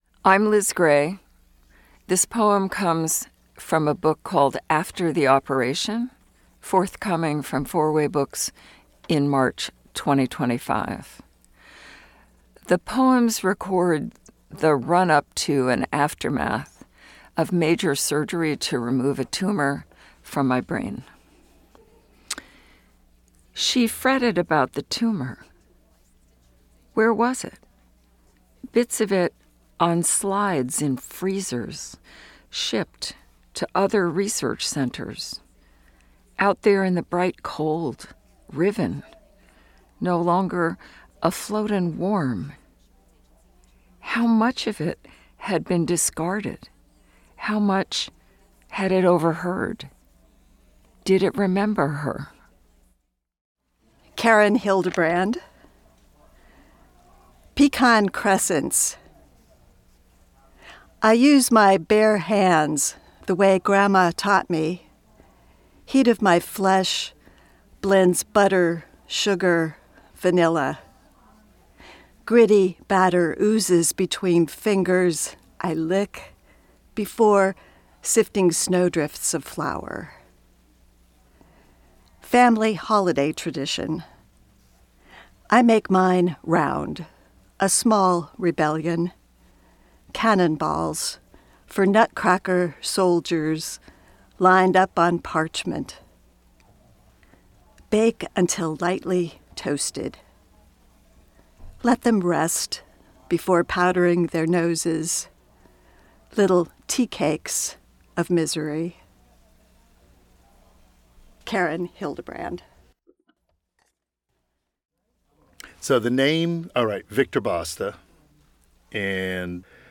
Please enjoy this audiobook anthology of poems and prose read by members of the Four Way Books community
an artist-owned studio in New York City.
Four-Way-Books-author-readings.mp3